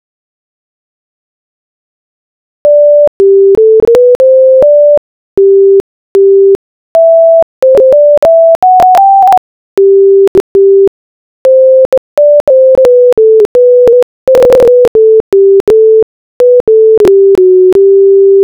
E tchaaaannnnnrrrãããã, a máquina está cantando Bach. Um pouco desafinada em algumas partes, ou perdida, mas todos sabemos que ‘Bach’ não é para qualquer um, logo vamos dar um desconto.
bach_minuetto_sint_flauta.wav